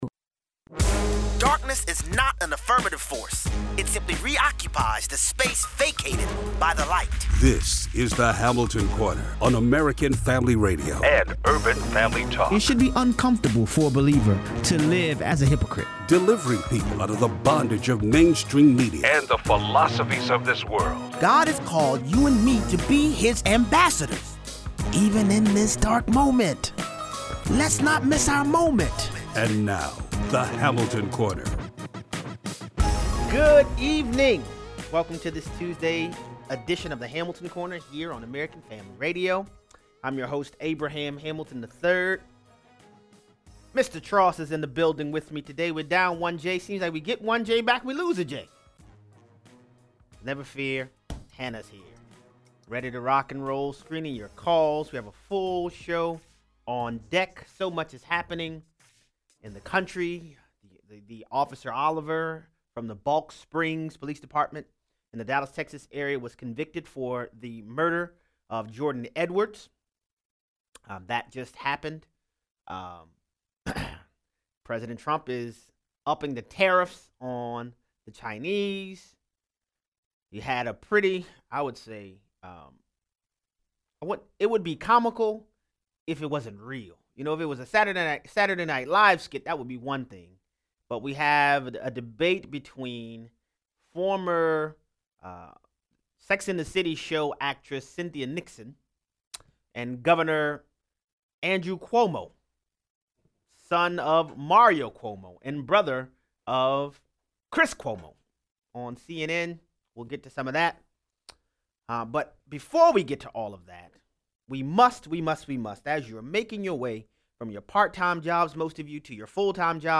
0:00 - 0:20: Exodus 18:13-24. The division of authority advised to Moses by Jethro served as the basis for the U.S. Constitution’s separation of powers features and the specific division of labor among the federal judiciary. 0:23 - 0:40: Andrew Cuomo vs. Cynthia Nixon debate in New York: did Cuomo just admit that he lies? 0:43 - 0:60: ESPN cries “uncle” as new show flops. Callers weigh in.